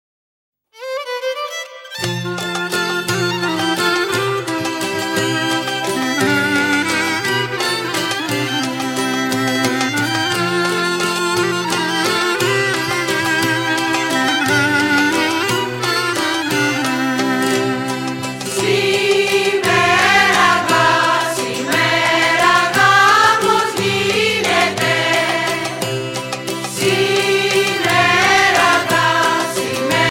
Σύλλογος παραδοσιακών χορών-χορωδίας